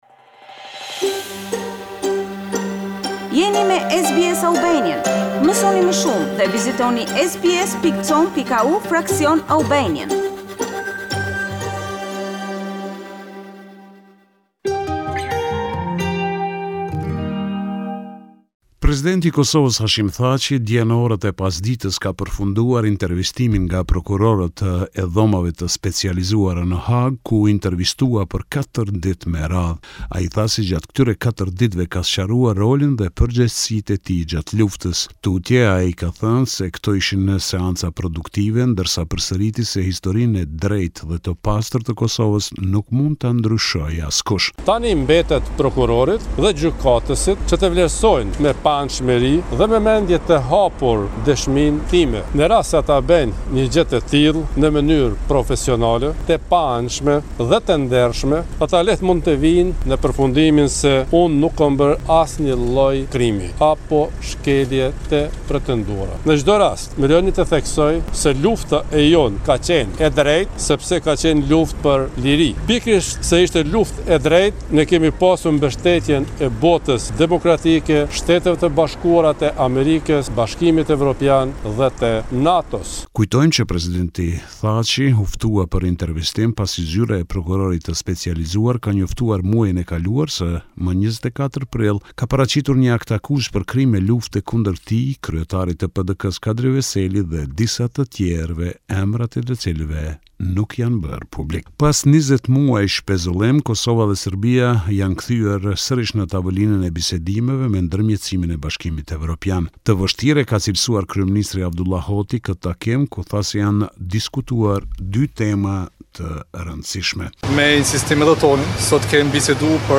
This is a report summarising the latest developments in news and current affairs in Kosove.